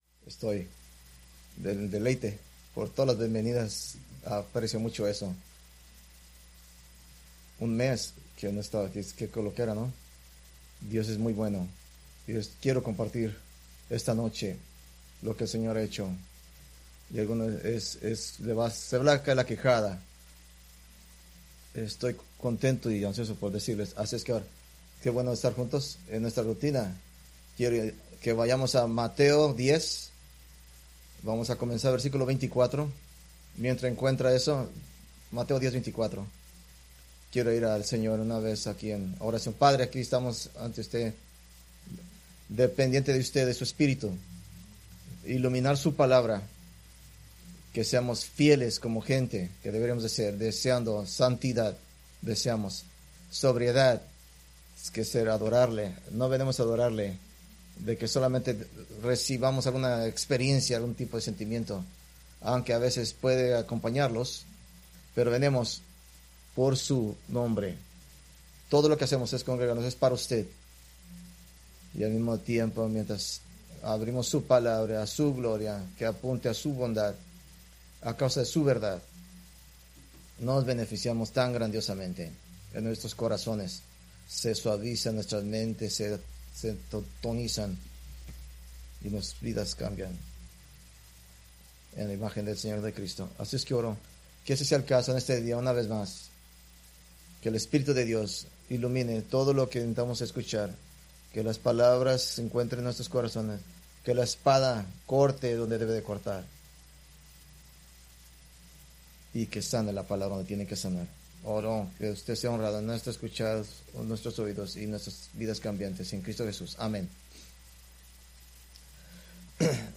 Preached June 8, 2025 from Mateo 10:24-33